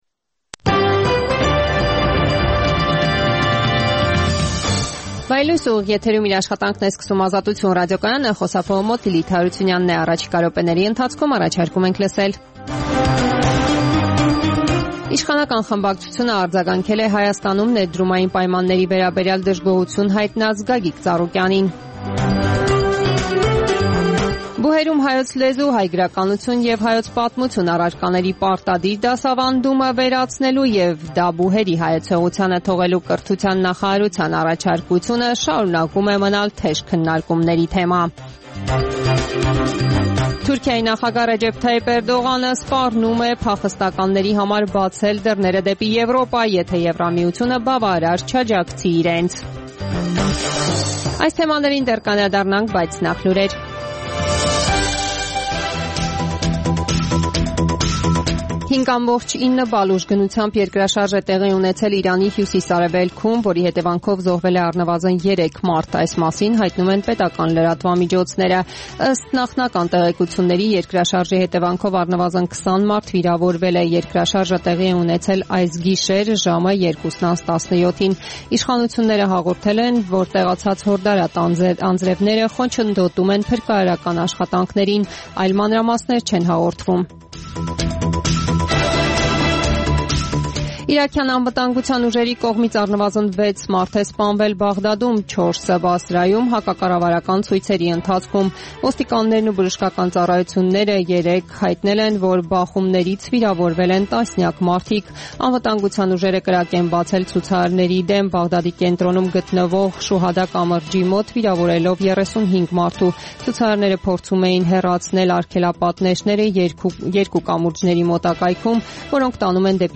Տեղական եւ միջազգային լուրեր, ռեպորտաժներ, հարցազրույցներ, տեղեկատվություն օրվա սպասվող իրադարձությունների մասին, մամուլի համառոտ տեսություն: